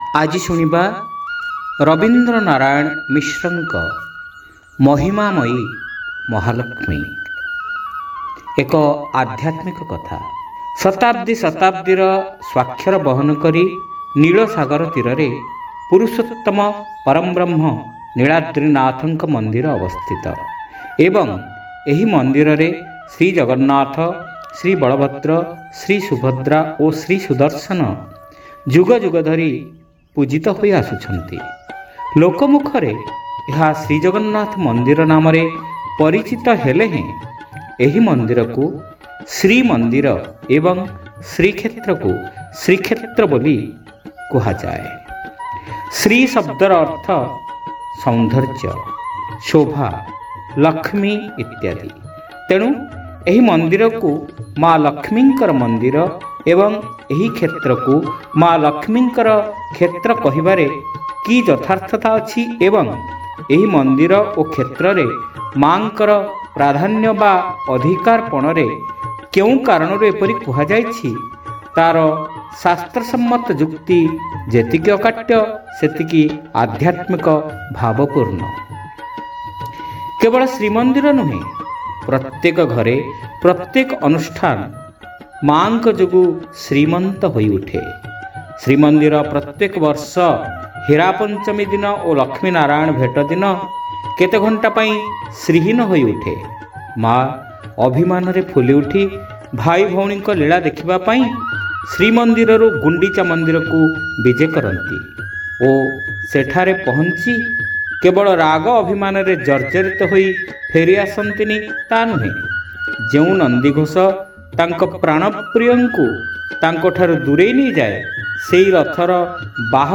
ଶ୍ରାବ୍ୟ ଗଳ୍ପ : ମହିଁମାମୟୀ ମହାଲକ୍ଷ୍ମୀ